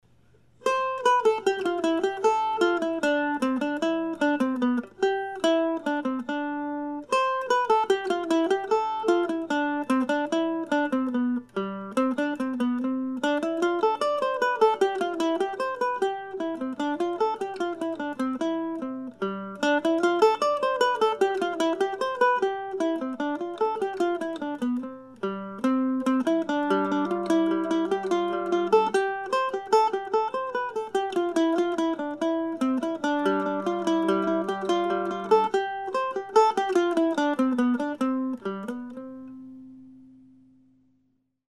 Here it is presented as a solo piece in the key of C.